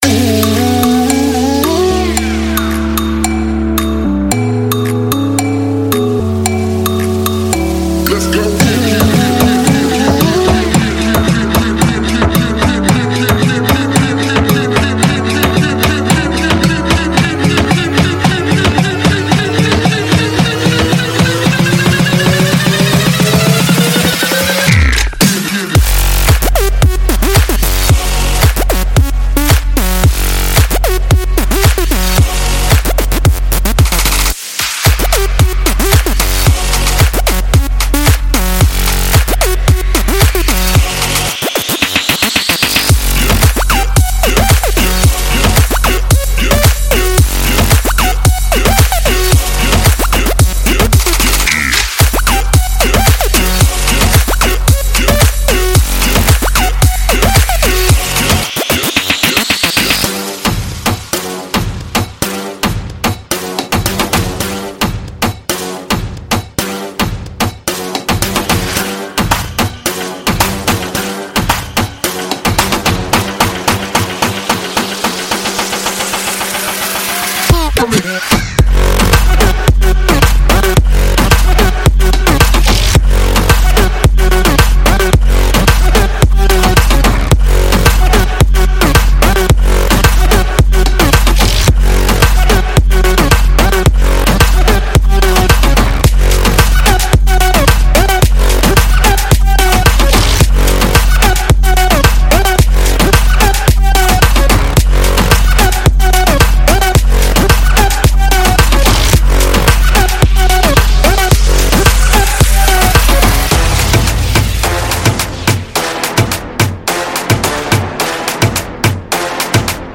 DubstepEDMHouse
Dubstep